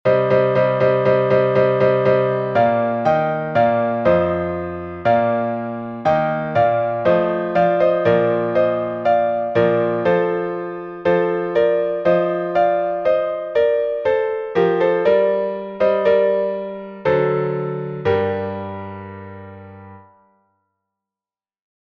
Глас 2